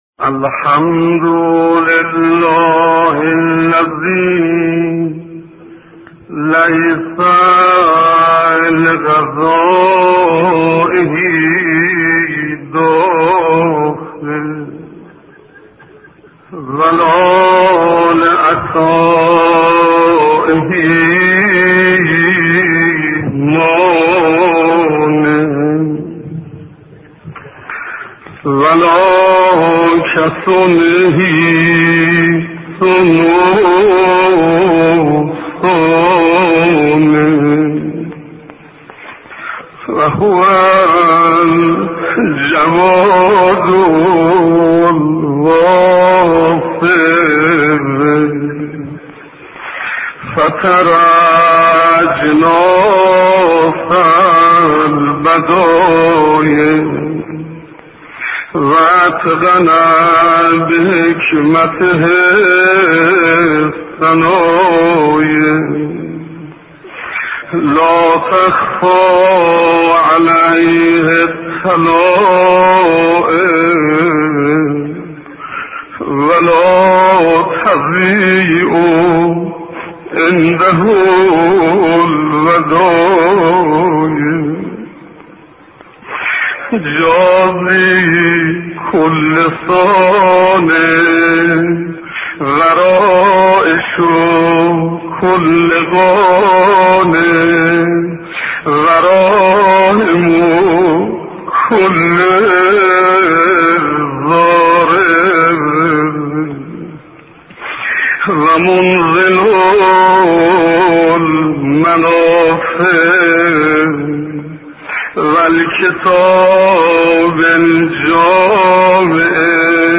Səslər | Ərəfə duası | Ziyaos-salehin
Hacı Şeyx Hüseyn Ənsariyanın səsilə